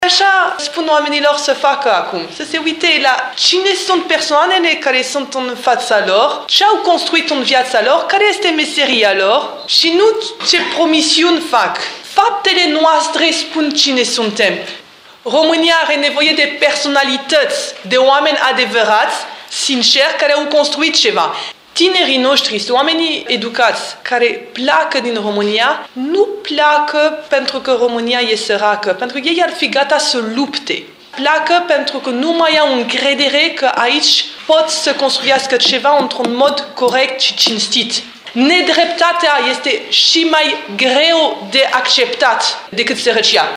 Ea a susținut o conferință de presă în cadrul căreia a detaliat programul electoral al USR, spunând că intenția este de depolitizare a societății.